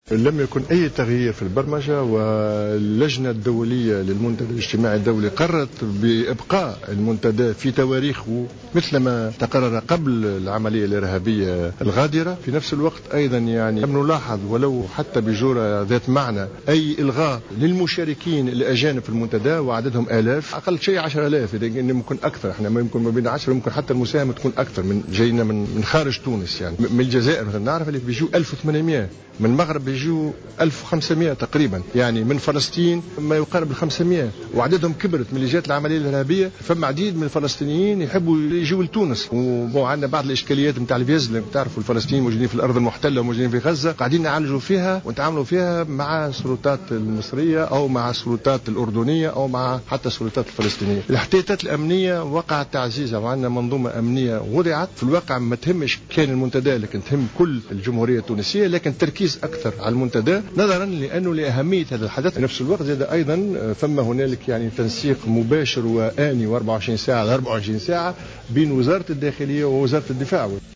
خلال ندوة صحفية عقدت اليوم للتعريف بآخرالاستعدادات للمنتدى